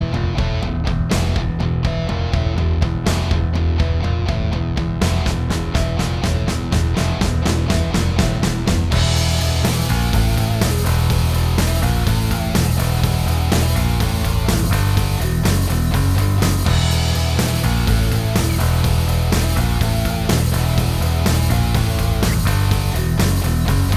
Minus Lead Guitar Rock 5:30 Buy £1.50